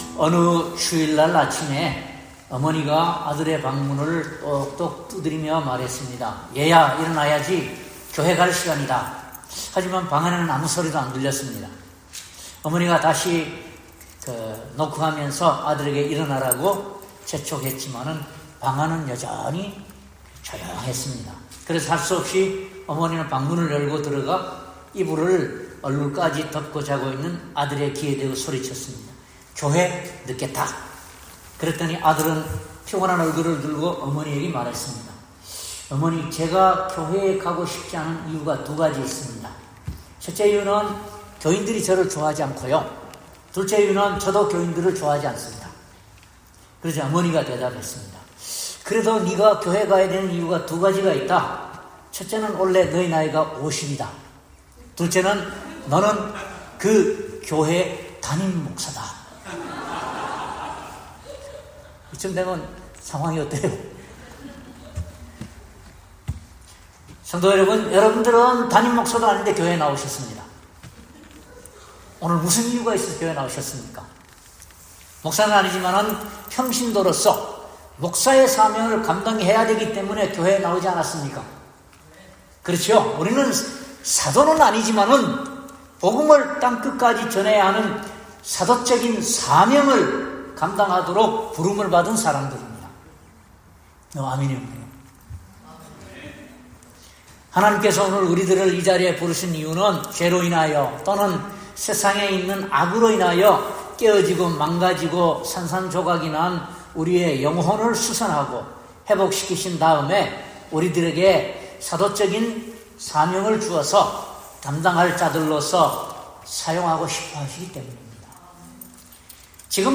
슥 1:7-17 Service Type: 특별집회 모든 성도는 복음을 땅끝까지 전해야 하는 사도적 사명을 감당하도록 부름을 받은 사람들입니다.